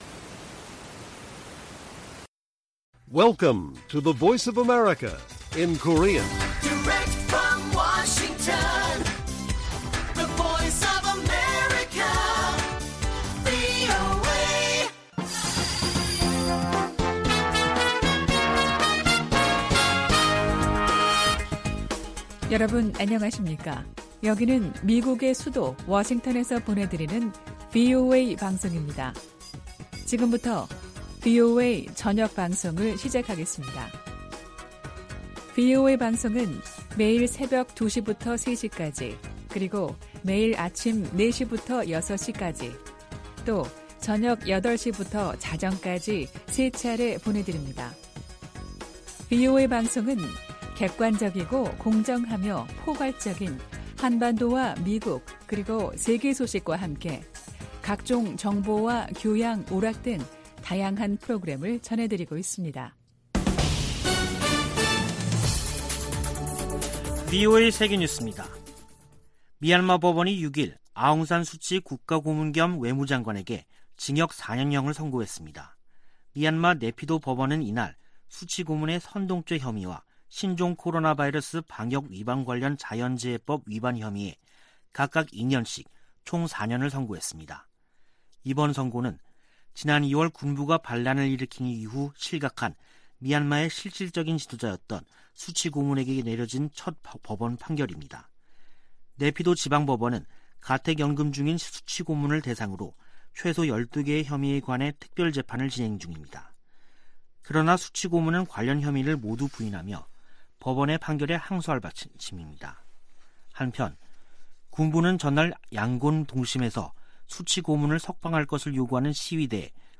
VOA 한국어 간판 뉴스 프로그램 '뉴스 투데이', 2021년 12월 6일 1부 방송입니다. 중국이 한반도 종전선언 추진에 지지 의사를 밝혔지만 북한은 연일 미국을 비난하며 냉담한 태도를 보이고 있습니다. 유엔이 올해에 이어 내년에도 북한을 인도지원 대상국에서 제외했다고 확인했습니다. 미국 유권자 42%는 조 바이든 행정부 출범 이후 미북 관계가 악화한 것으로 생각한다는 조사 결과가 나왔습니다.